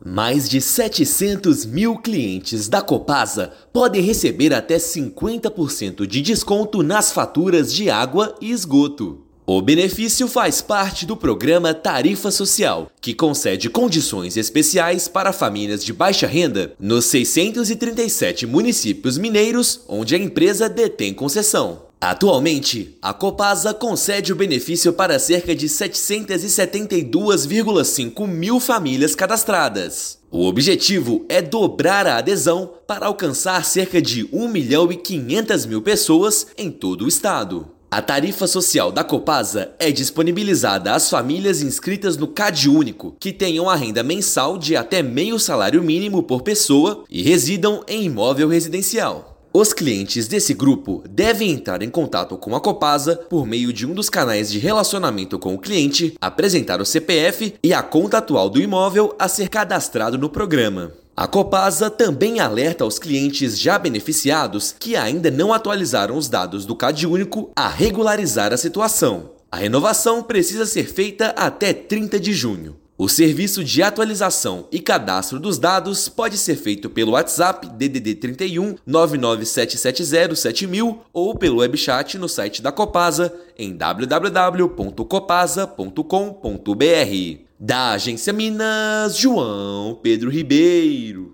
Outras 103 mil famílias podem perder a tarifa social se não atualizarem o cadastro até 30/6; saiba como aderir ao benefício . Ouça matéria de rádio.